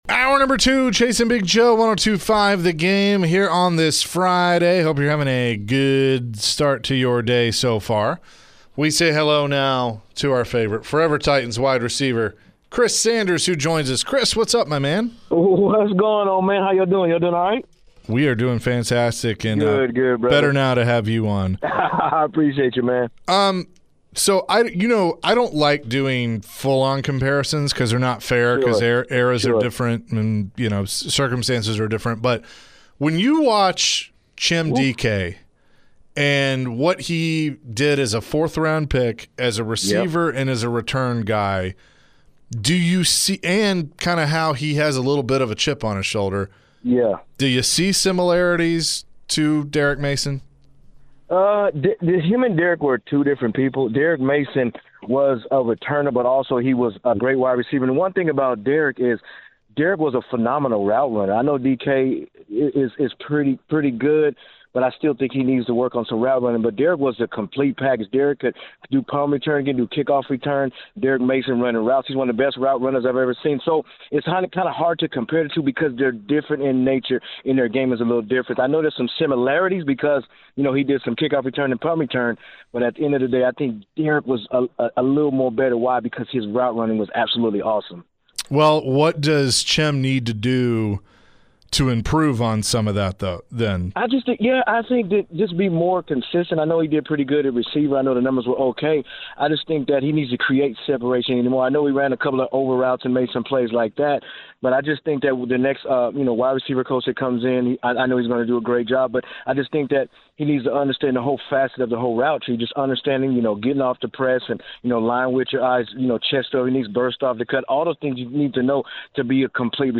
The guys chatted with Forever Titans WR Chris Sanders. Chris shared his thoughts on the Titans' upcoming offseason. Who should the Titans get in free agency?